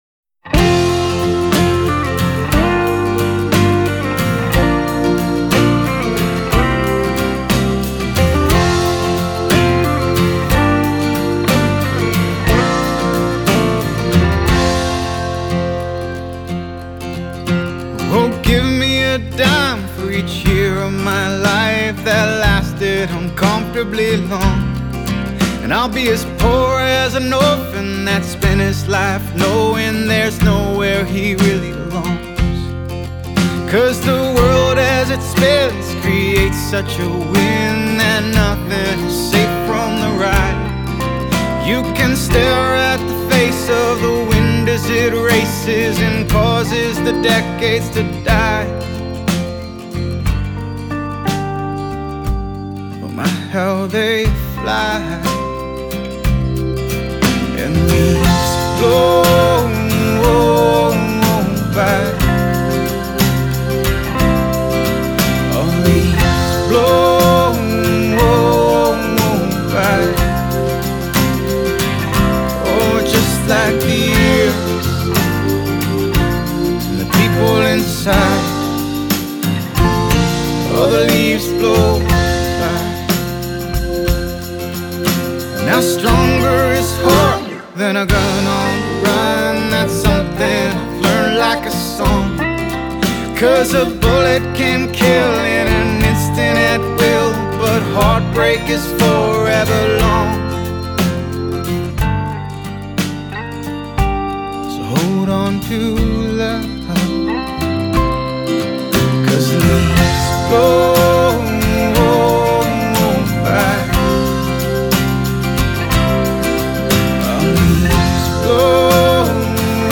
"Elegant Folk" music